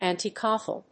アクセント・音節ànti・Cátholic